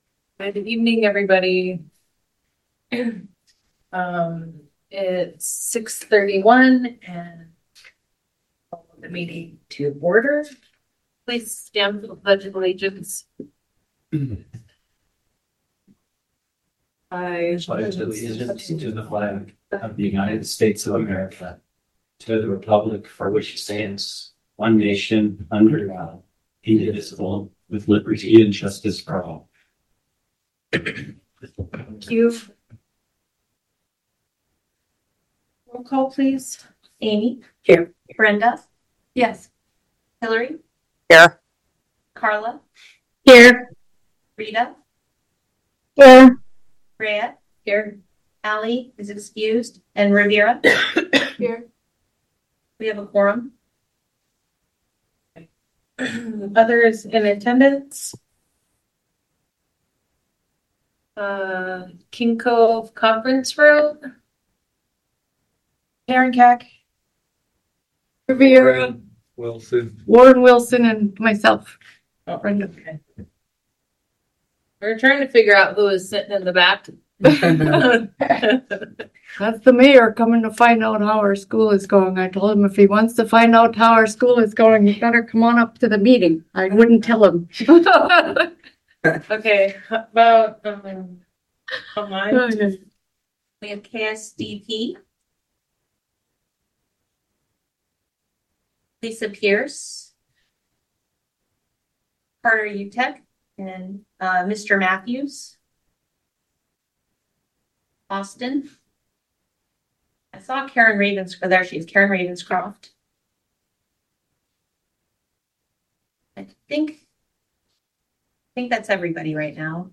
This meeting is open to the public at the District Office in Sand Point, borough schools & via Zoom.